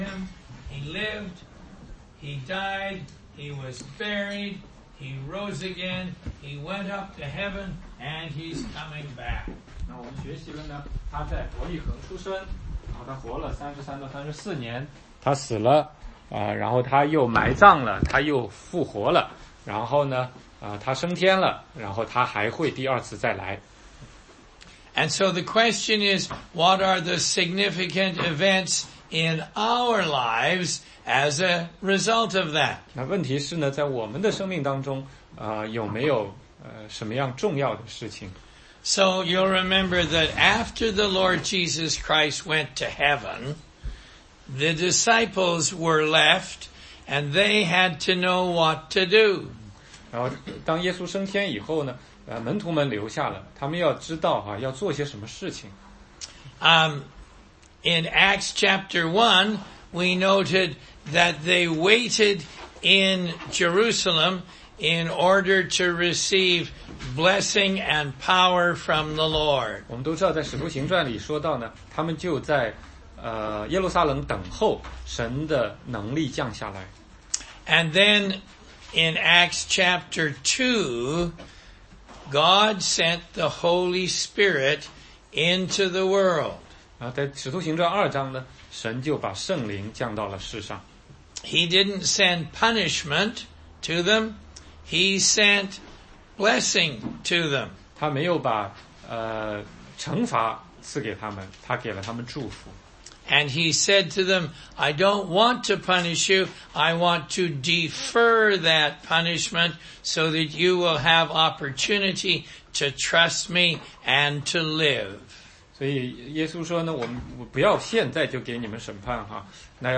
16街讲道录音 - 圣灵的工作